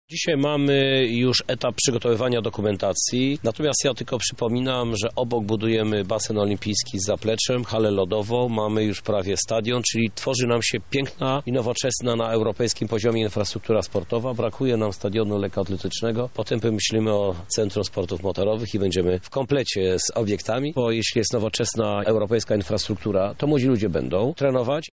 Chcemy stworzyć nowoczesną, europejską infrastrukturę sportową – mówi Krzysztof Żuk, prezydent Lublina